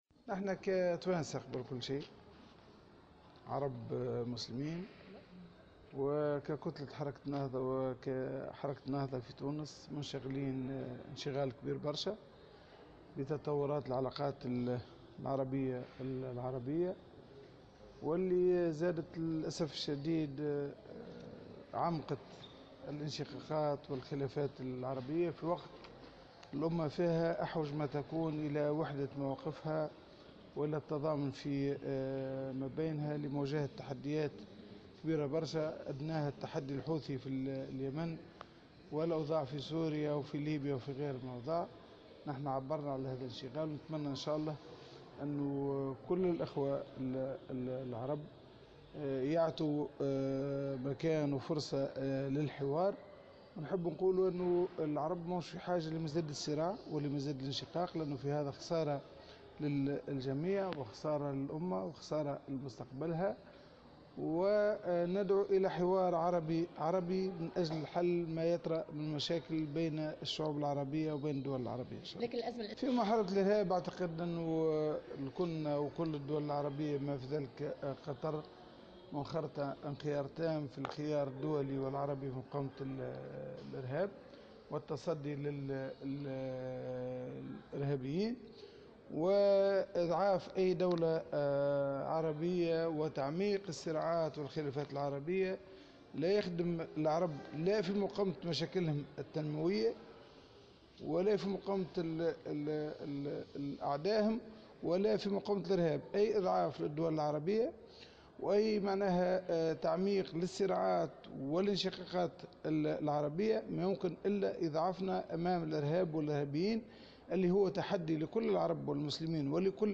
وأكد في تصريح لمراسلة "الجوهرة اف أم" أن قطر منخرطة في الخيار الدولي والعربي لمقاومة الارهاب.